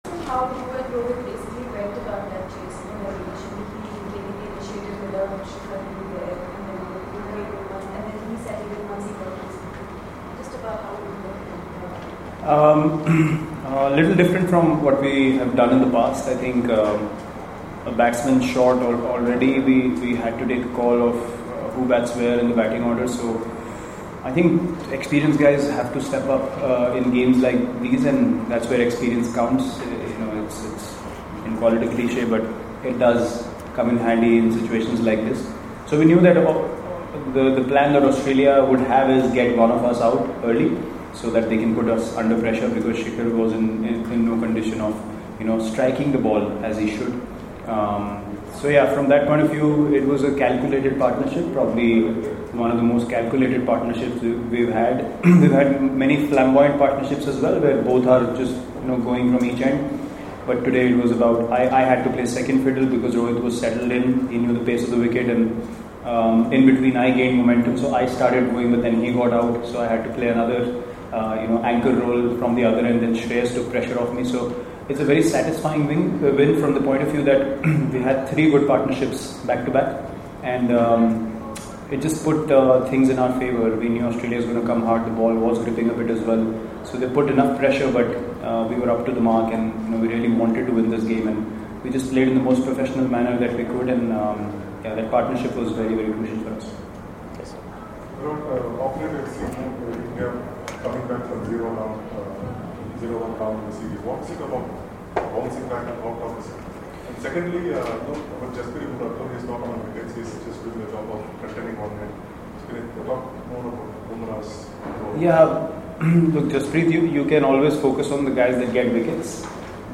Virat Kohli spoke to the media in Bengaluru on Sunday after the 3rd ODI against Australia.